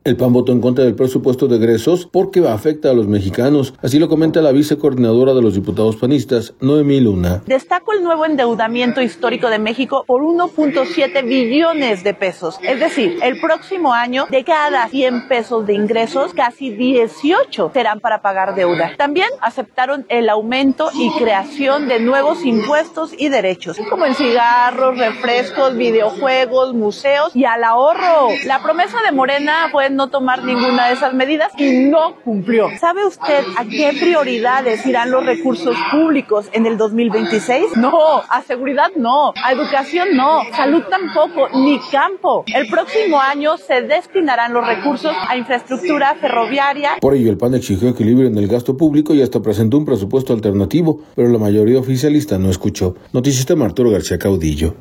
El PAN votó en contra del Presupuesto de Egresos de la Federación 2026 porque afecta a los mexicanos, no sólo con el incremento en la deuda, sino con obras faraónicas de infraestructura, así lo comenta la vice coordinadora de los diputados panistas, Noemí Luna.